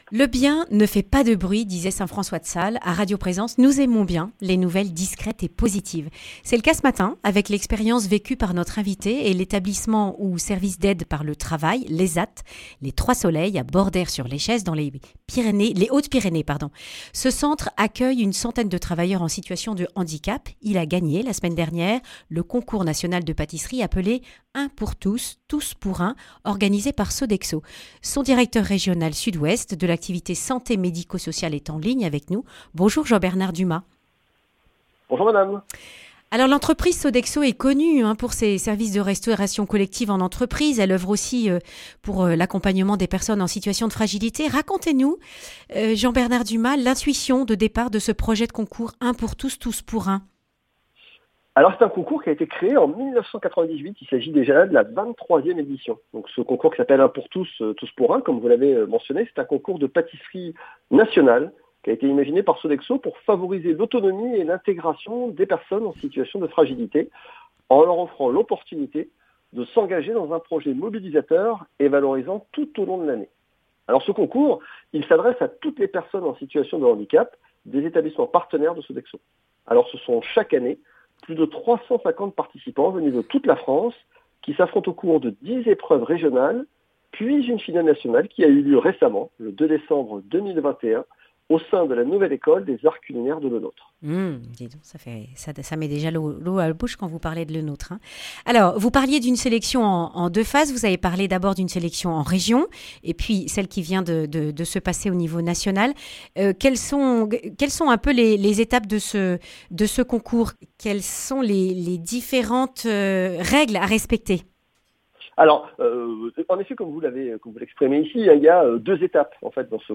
Accueil \ Emissions \ Information \ Régionale \ Le grand entretien \ L’autonomie des personnes handicapées valorisée par le concours de cuisine (…)